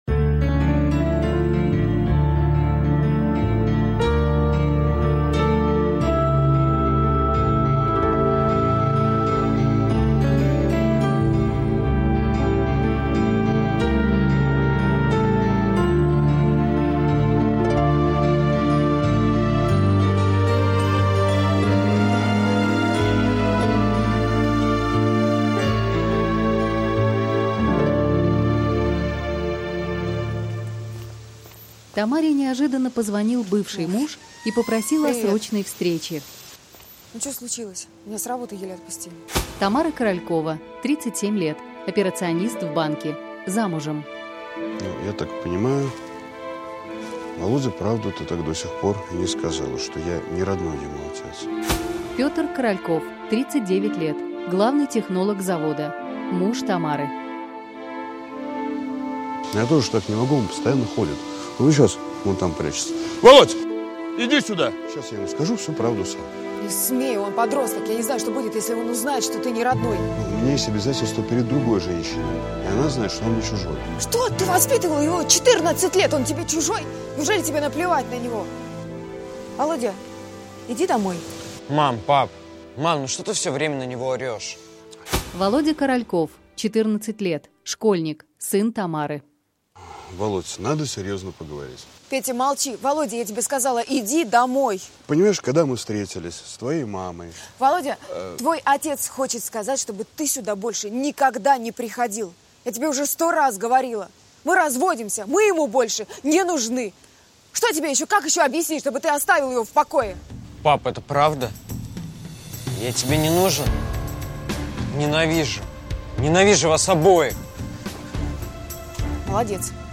Аудиокнига Замуж за мужа | Библиотека аудиокниг